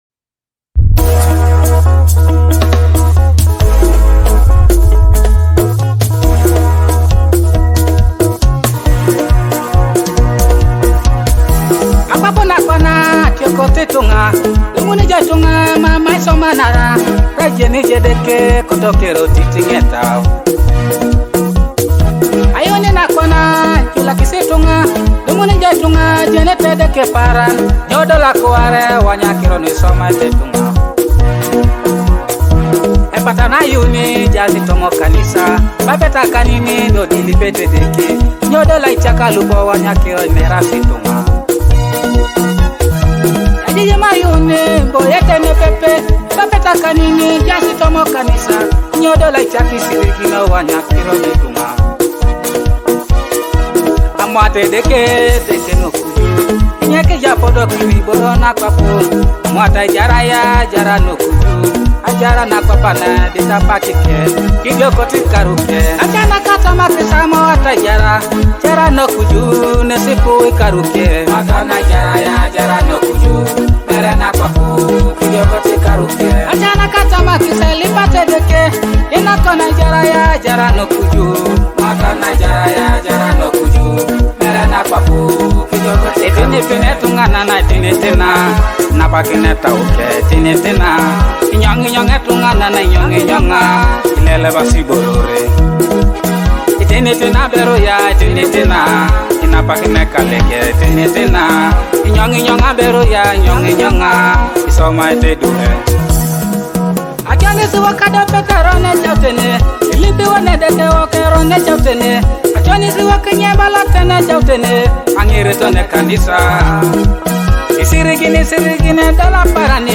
Uplifting track
gospel song
uplifting vocals